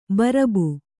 ♪ barabu